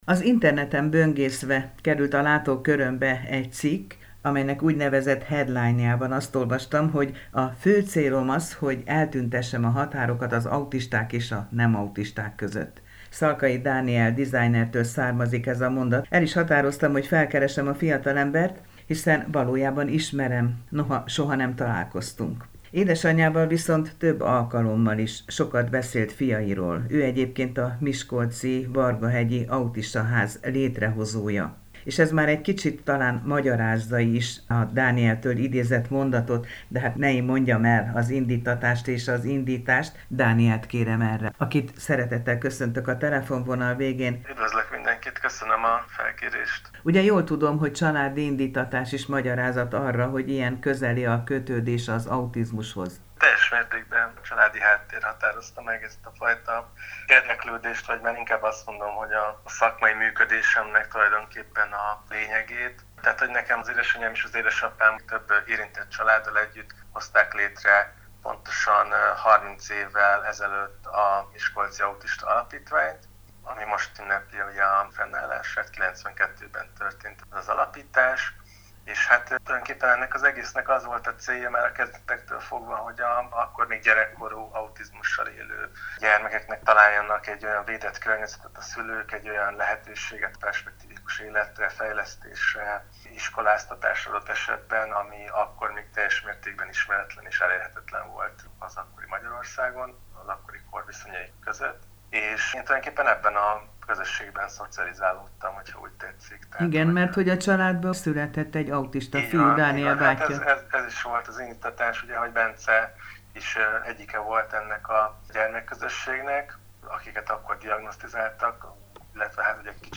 Elköteleződéséről és terveiről beszélt a Csillagpont Rádió műsorában.